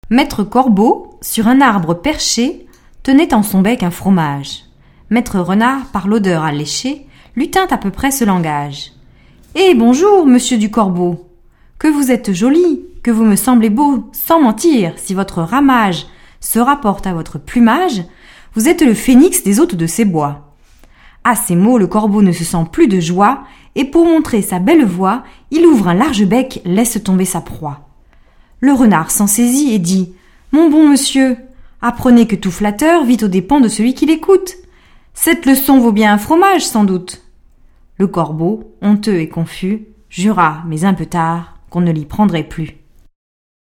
Nous avons pu réaliser les enregistrements suivants, permettant ainsi à chacun de découvrir l'enregistrement d'une lecture.